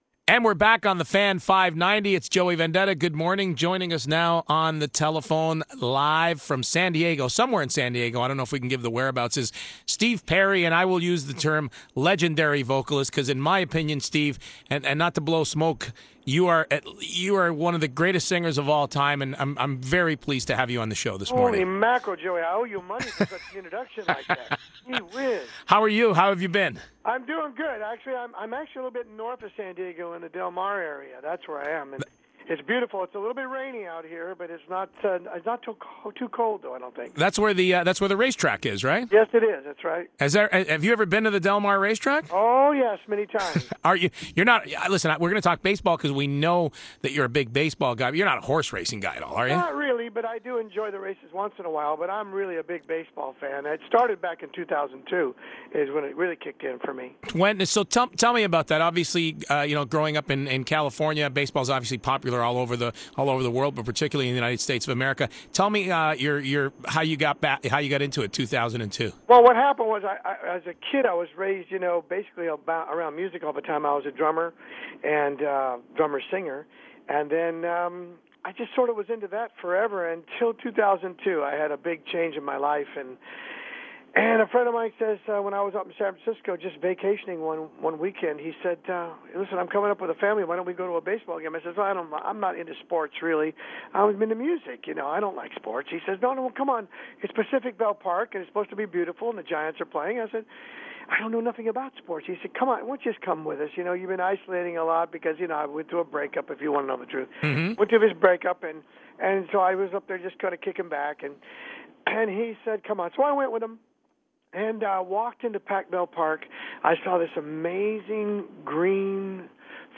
New Steve Perry interview: Dec. 23, 2010